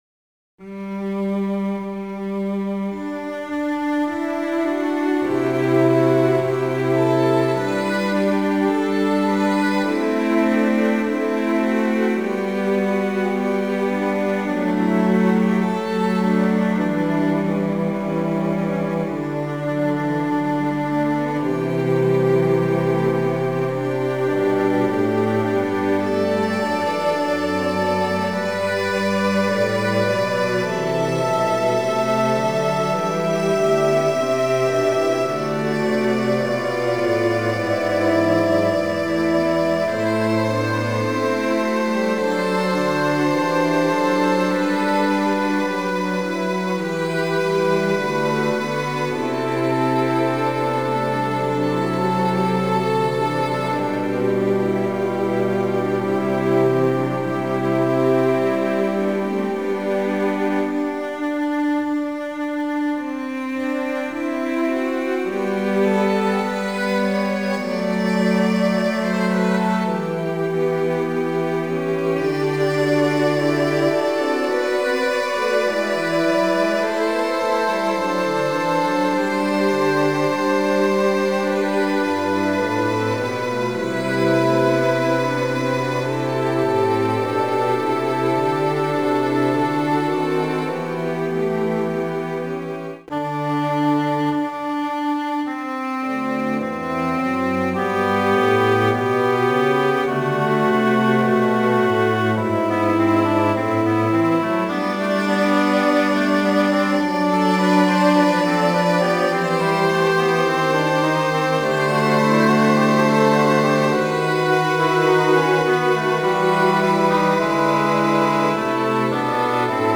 Midi Instrumental ensemble (Fl-Fl-Fl-Fg-Str ⇒ Fl-Ob-Eh-Fg-Str)